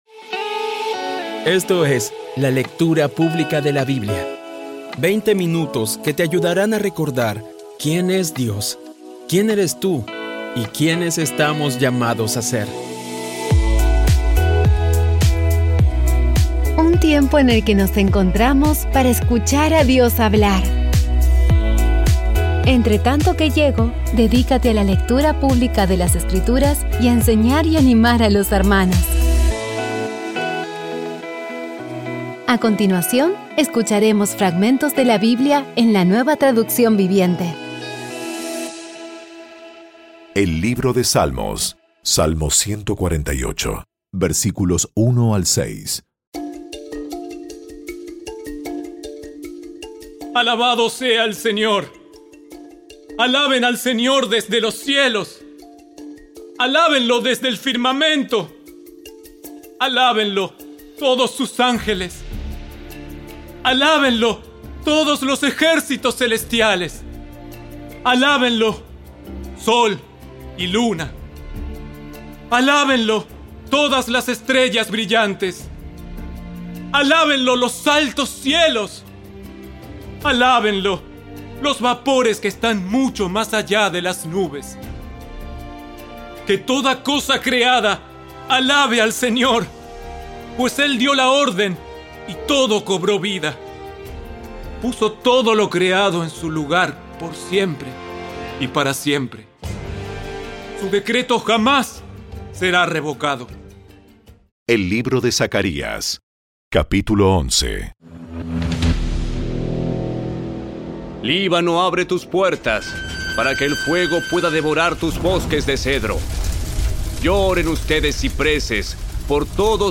Audio Biblia Dramatizada Episodio 362
Poco a poco y con las maravillosas voces actuadas de los protagonistas vas degustando las palabras de esa guía que Dios nos dio.